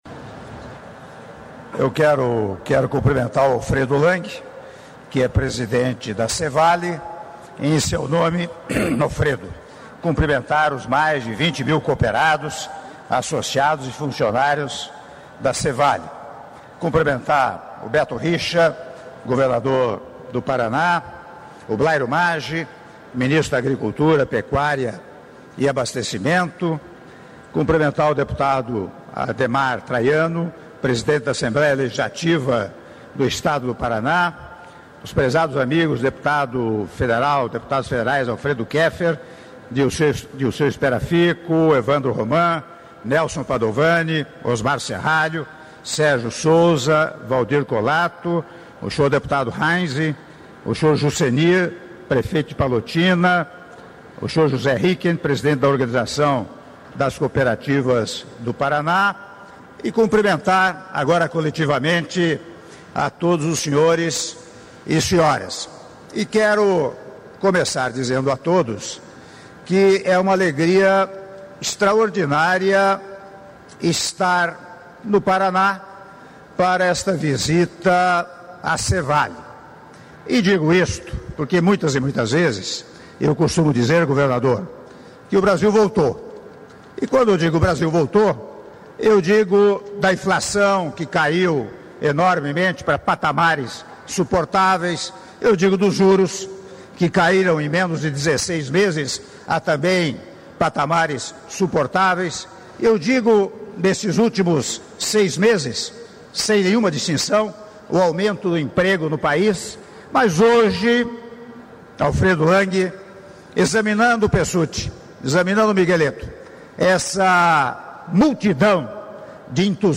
Áudio do discurso do Presidente da República, Michel Temer, durante solenidade de comemoração aos 20 anos do Complexo Agroindustrial e inauguração do maior abatedouro de peixes do Brasil - Palotina/PR(06min56s)